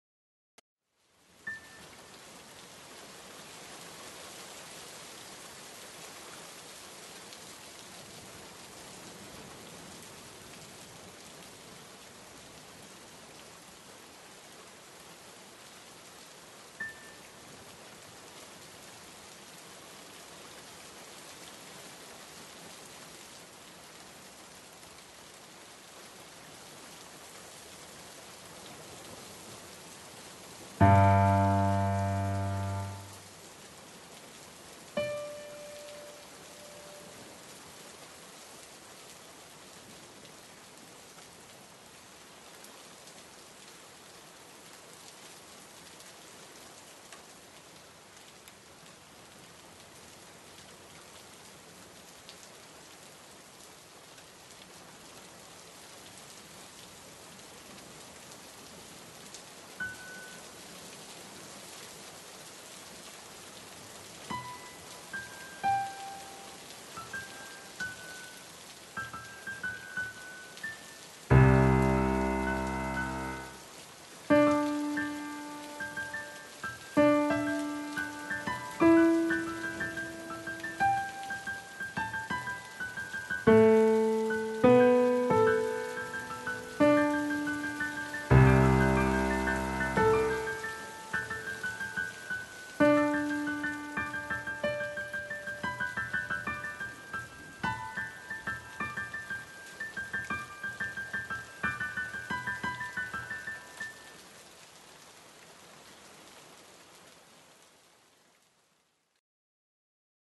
Nagranie obrazujące uderzenia meteorytów w naszą planetę. Dane z pliku .csv (masa obiektu oraz rok trafienia) zostały zamienione na dźwięki MIDI a następnie przy pomocy DAW przekształcone na nagranie. Im większa masa obiektu tym niższy i głośniejszy dźwięk.
W tle słychać opady deszczu.